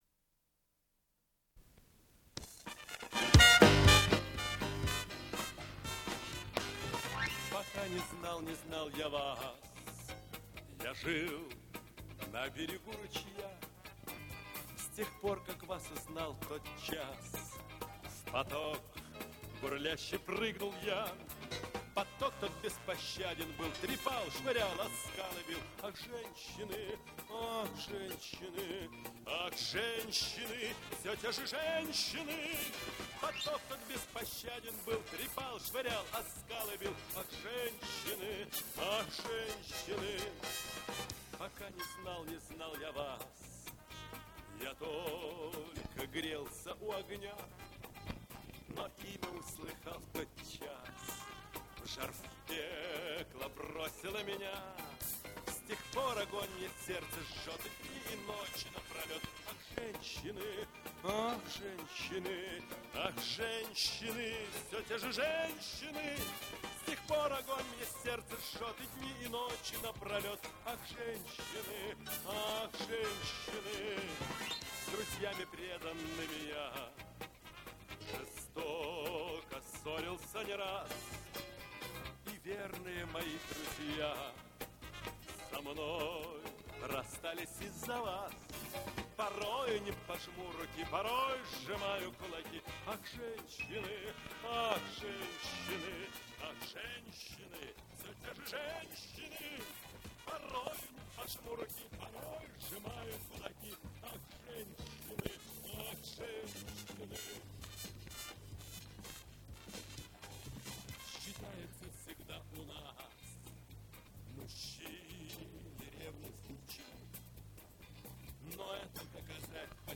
Сопровождение инструментальный ансамбль
Запись 1980 год Дубль моно.